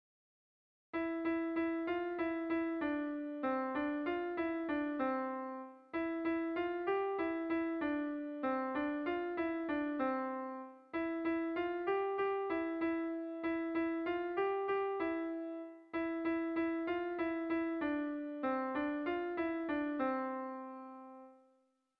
Kontakizunezkoa
Zortziko txikia (hg) / Lau puntuko txikia (ip)
AABA